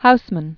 (housmən), A(lfred) E(dward) 1859-1936.